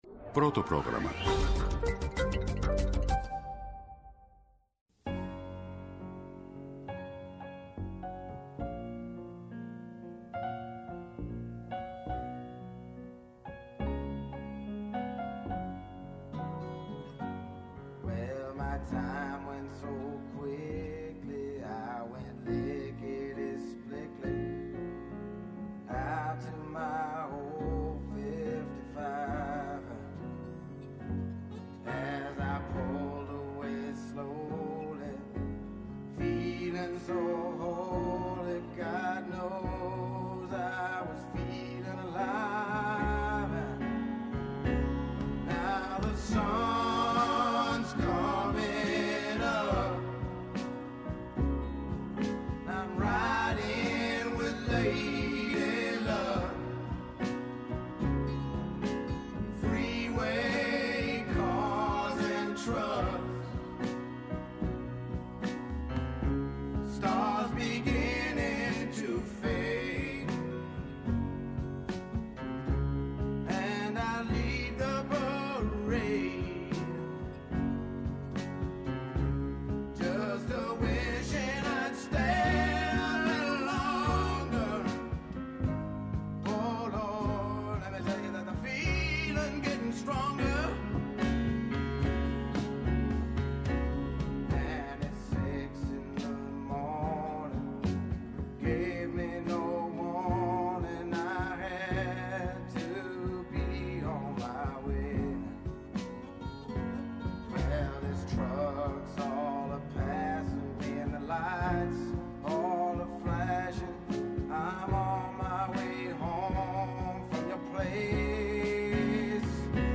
Άνθρωποι της επιστήμης, της ακαδημαϊκής κοινότητας, πολιτικοί, ευρωβουλευτές, εκπρόσωποι Μη Κυβερνητικών Οργανώσεων και της Κοινωνίας των Πολιτών, συζητούν για όλα τα τρέχοντα και διηνεκή ζητήματα που απασχολούν τη ζωή όλων μας, από την Ελλάδα και την Ευρώπη μέχρι την άκρη του κόσμου. ΠΡΩΤΟ ΠΡΟΓΡΑΜΜΑ